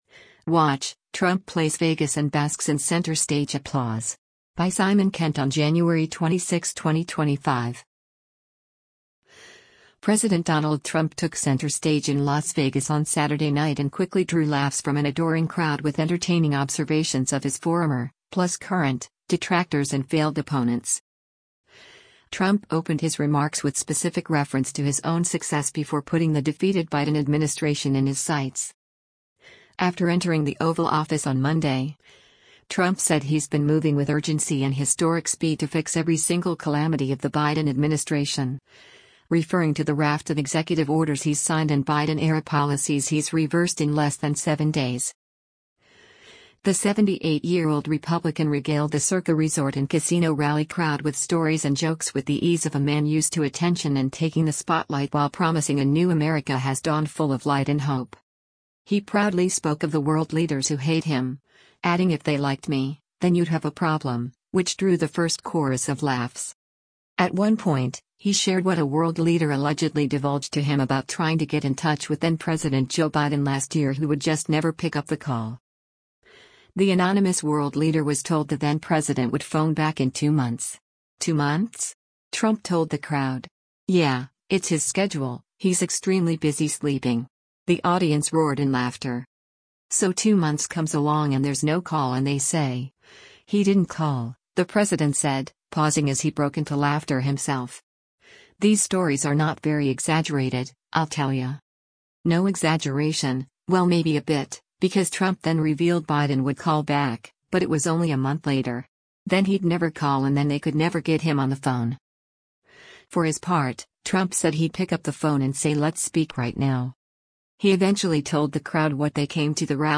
President Donald Trump took center stage in Las Vegas on Saturday night and quickly drew laughs from an adoring crowd with entertaining observations of his former – plus current – detractors and failed opponents.
He proudly spoke of the world leaders who hate him, adding “if they liked me, then you’d have a problem,” which drew the first chorus of laughs.